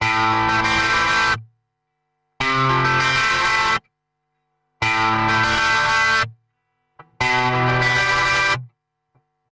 121 Bpm桑巴吉他
描述：韵律吉他，Fender Tele。和弦。FMa7 Gm7 C7 C13
Tag: 121 bpm Samba Loops Guitar Electric Loops 1.25 MB wav Key : F